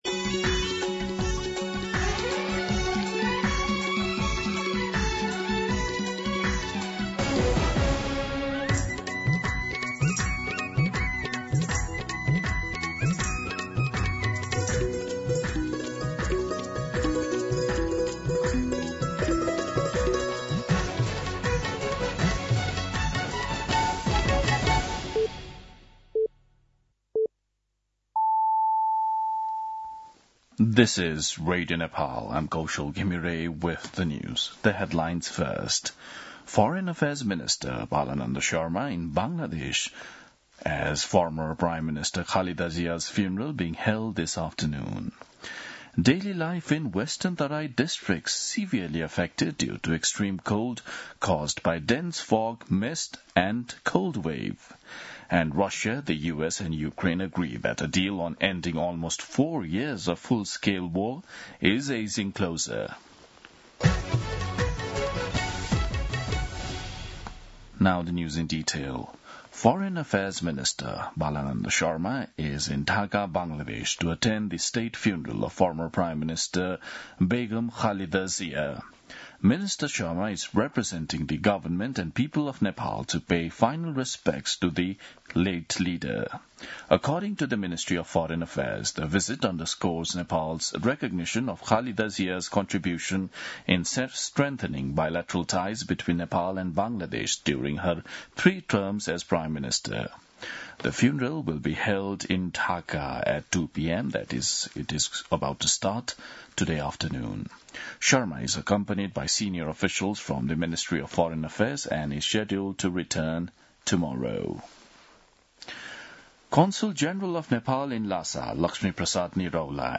दिउँसो २ बजेको अङ्ग्रेजी समाचार : १६ पुष , २०८२
2pm-English-News-9-16.mp3